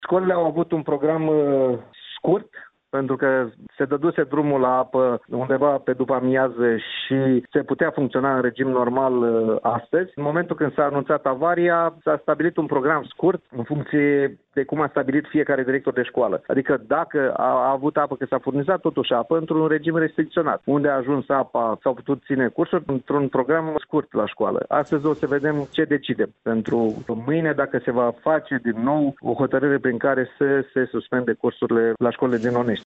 Prefectul de Bacău, Valentin Ivancea: